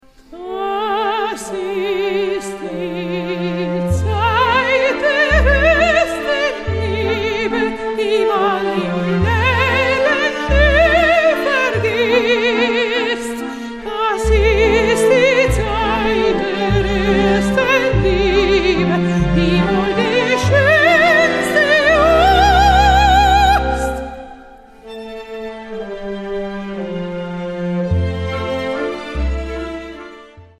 The CD contains both vocal and orchestral pieces.
* World première modern recordings